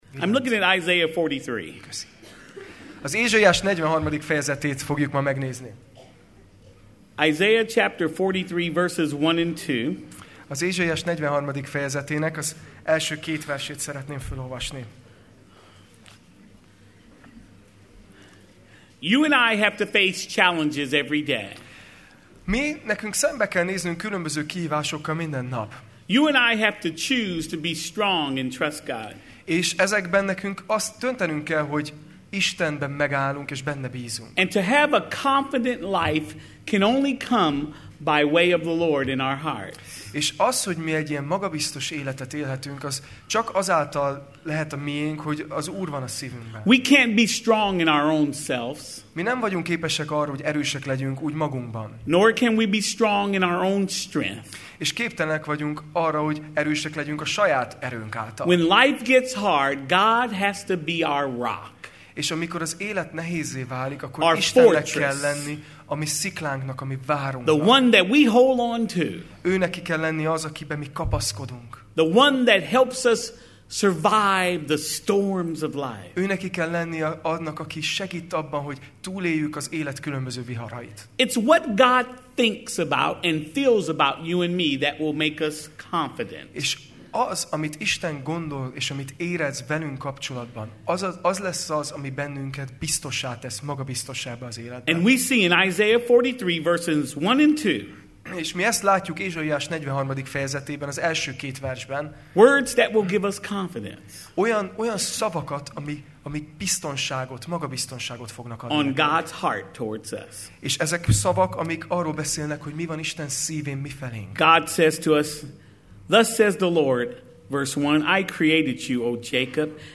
Sorozat: Tematikus tanítás Alkalom: Vasárnap Este